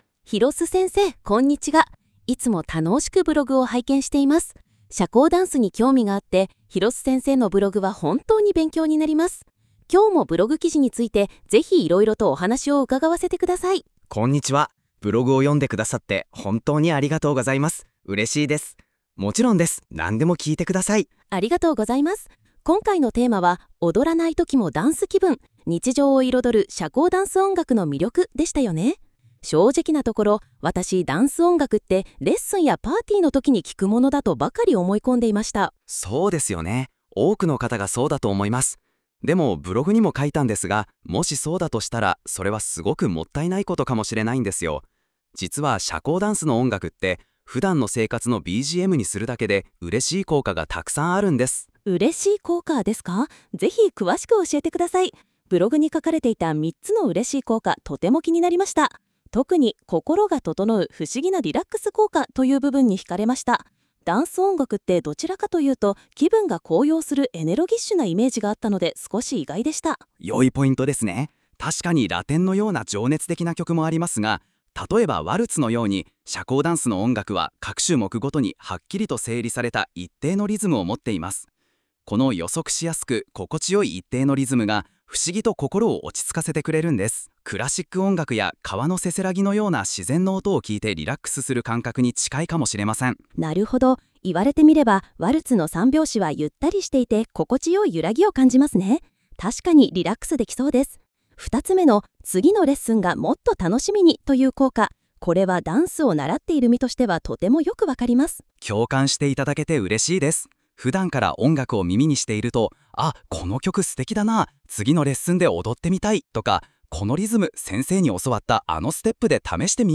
このブログは、約6分間の会話音声も公開しています。